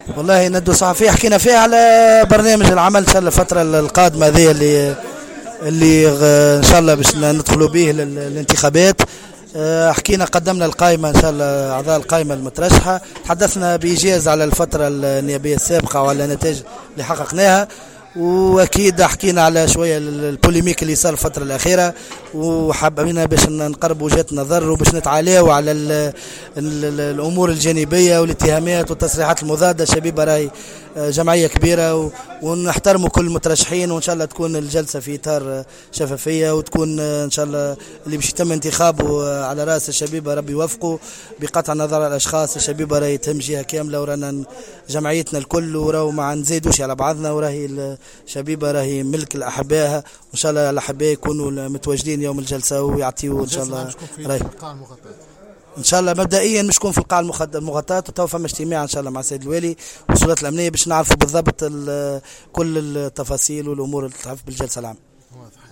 ندوة صحفية